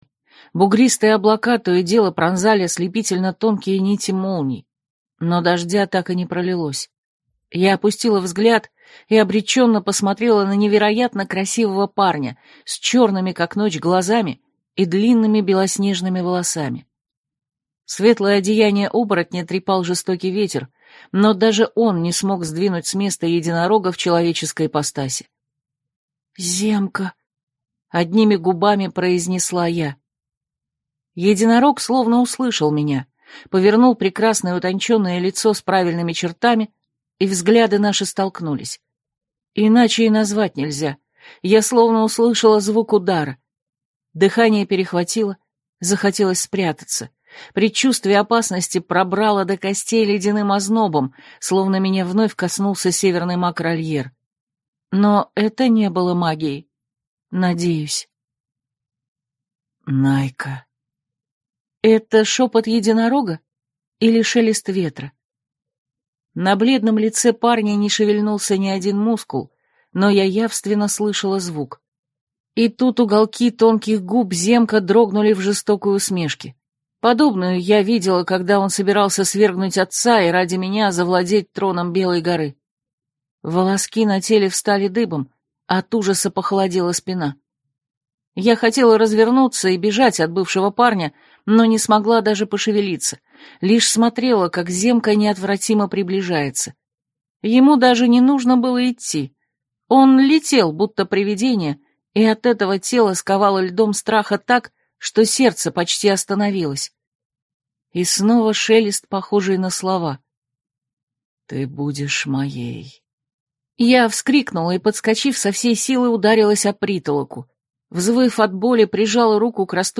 Аудиокнига Академия оборотней: нестандартные. Книга 4 | Библиотека аудиокниг